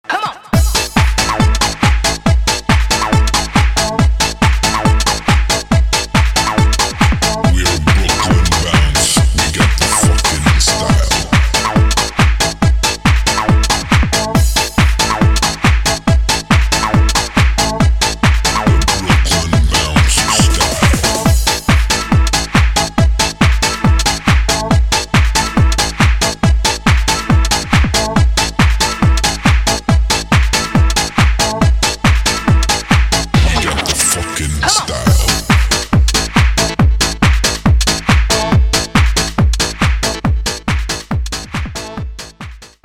dance
club